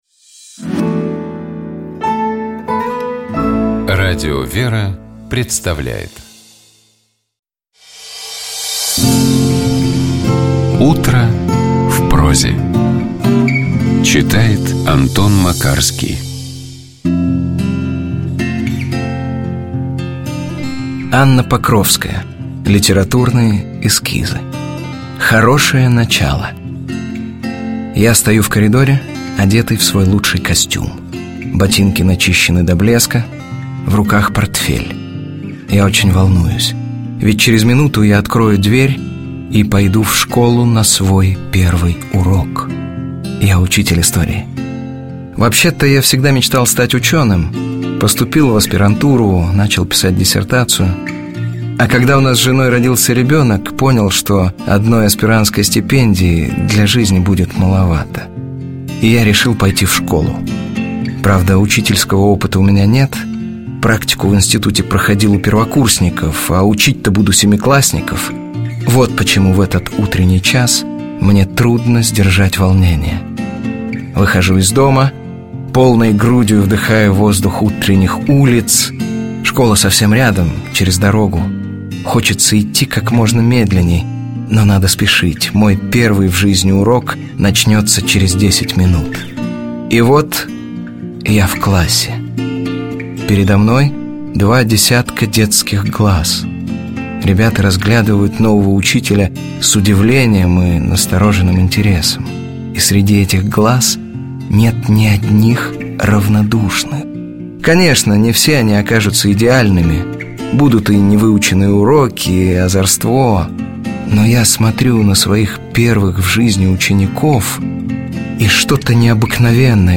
Текст Анны Покровской читает Антон Макарский.